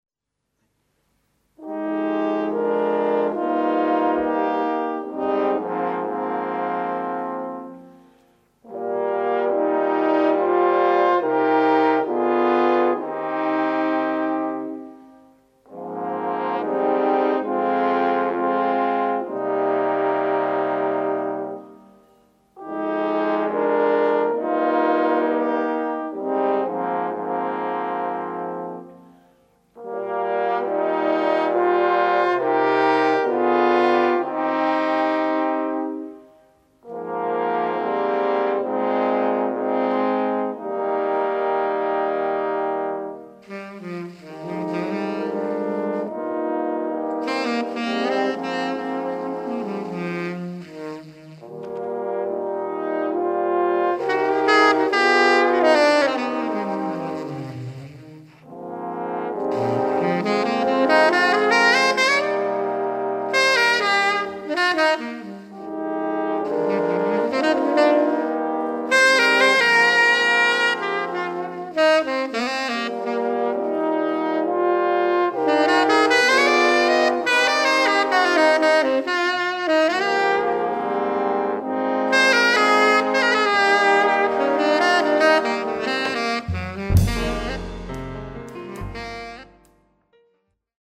oboa, angleški rog, bas klarinet, flavte
tenorski in sopranski saksofon
klavir, dirigiranje
kontrabas
bobni, tolkala
francoski rog
glas